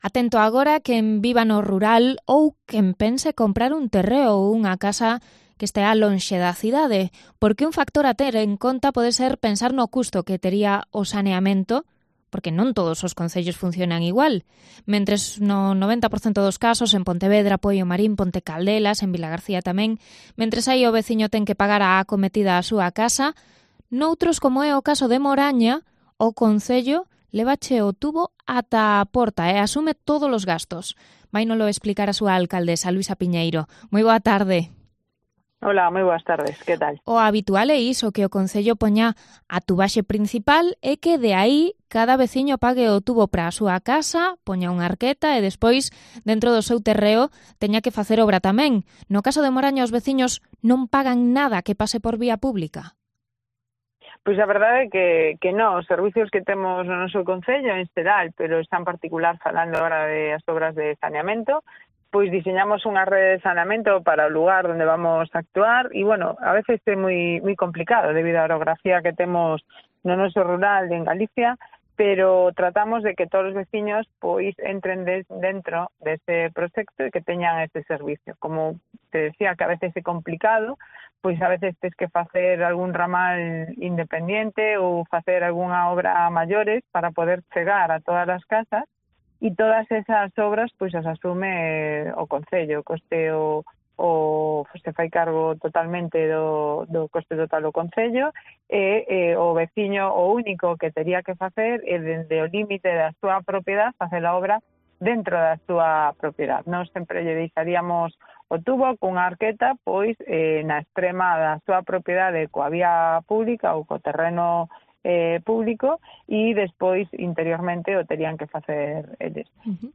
Entrevistas a Luisa Piñeiro